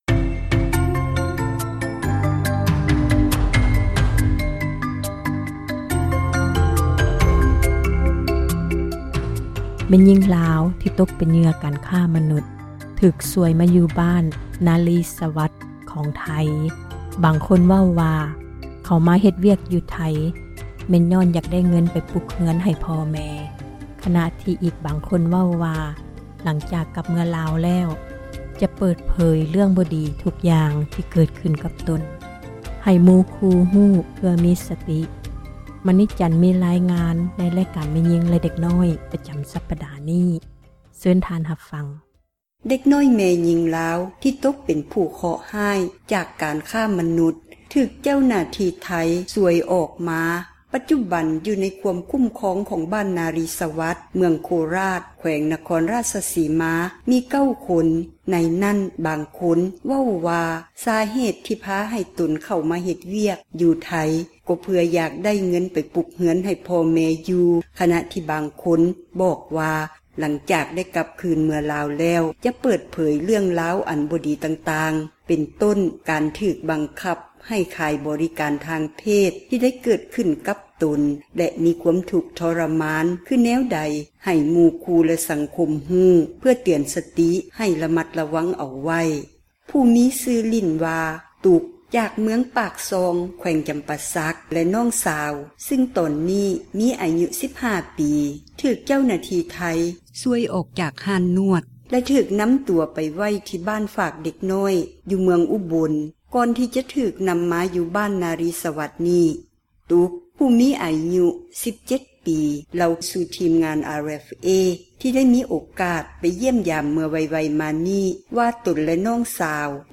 ມີຣາຍງານ